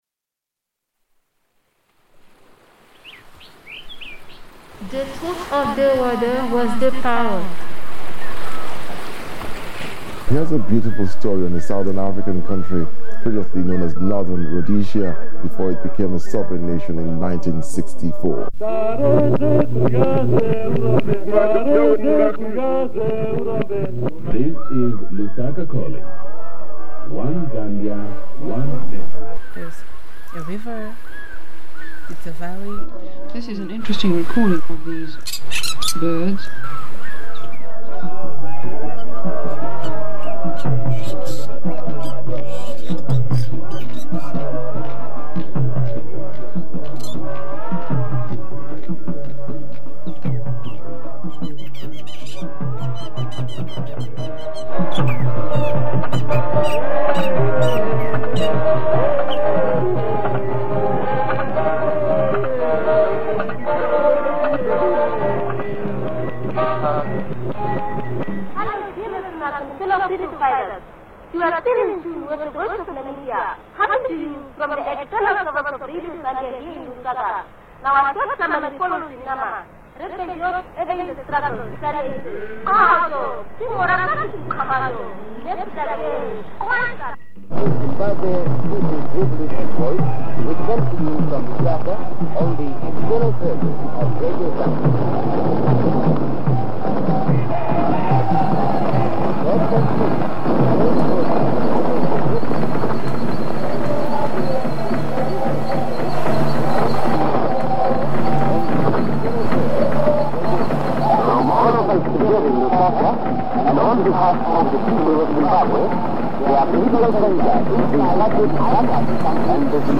Imagine tuning a radio time-machine dial between the past and present of the Zambezi River valley; birds weave a tapestry in and out of the soundscape as static, magnetic drift, crosstalk and interference rise and fall with the signals in the atmosphere. The foundation of this mix, an original recording in the Pitt Rivers Museum collection, is a radio broadcast of singing and drumming by an unidentified group circa 1965, in the first years of Zambia's independence and the period of transition from colonial broadcasting structures to a national network.
In transmissions of solidarity and strategy sent across borders, the sound of gunfire was a form of station identification, heard here among victory celebrations and swarming mosquitoes.
During the copying process one of the reels of tape was accidentally overprinted, so that one track runs backwards while another simultaneously runs forward. In my reimagined mix this glitch leads us through history and memory, the reversed rhythms of backwards magnetic tape conducting an aural transition back into the past.